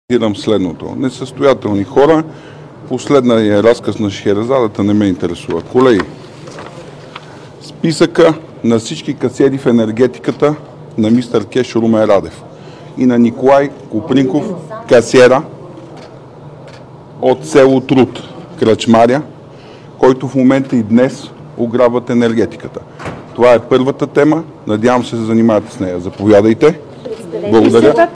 - директно от мястото на събитието (Народното събрание)